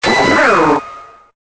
Cri de Lançargot dans Pokémon Épée et Bouclier.